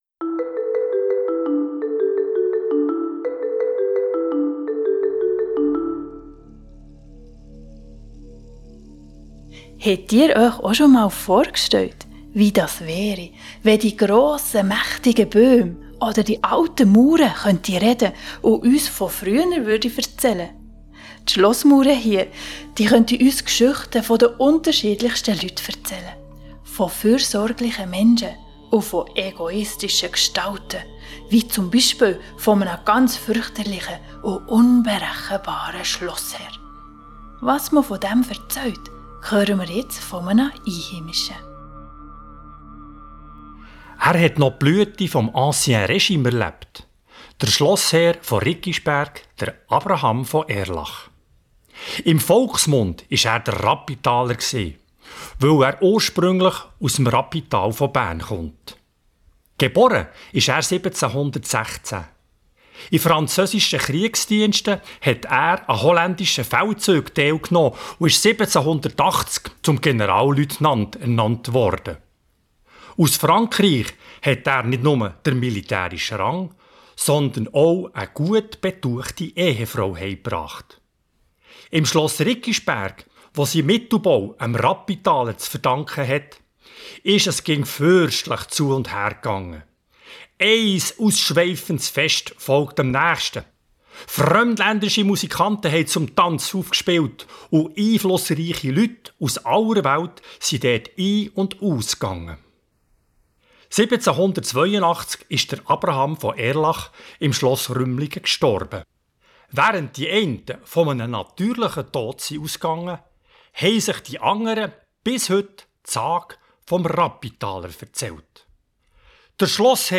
4_riggisberg_audiodatei-der-rabbentaler_sagenroute-gantrisch_c_bern-welcome.mp3